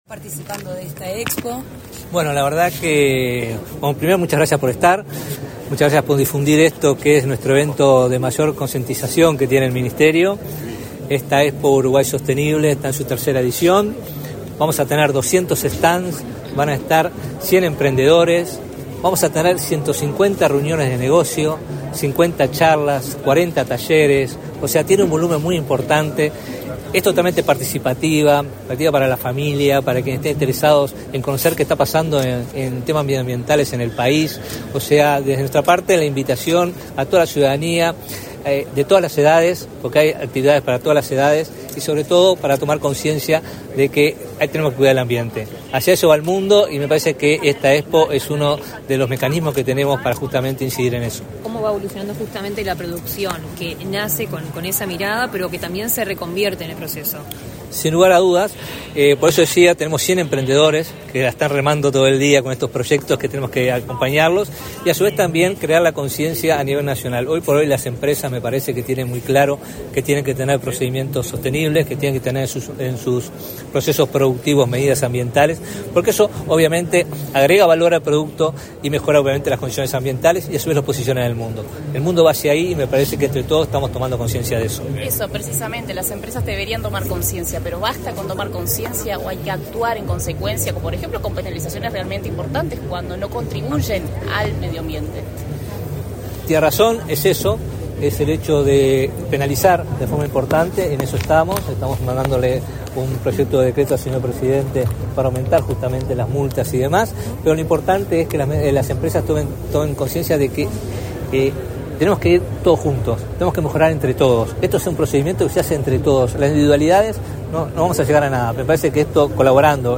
Declaraciones a la prensa del ministro de Ambiente, Robert Bouvier
Declaraciones a la prensa del ministro de Ambiente, Robert Bouvier 06/06/2024 Compartir Facebook X Copiar enlace WhatsApp LinkedIn El ministro de Ambiente, Robert Bouvier, participó, este 6 de junio, en la inauguración de la Expo Uruguay Sostenible. Tras el evento, realizó declaraciones a la prensa.